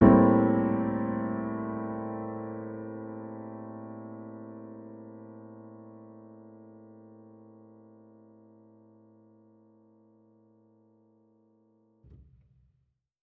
Index of /musicradar/jazz-keys-samples/Chord Hits/Acoustic Piano 2
JK_AcPiano2_Chord-Am7b9.wav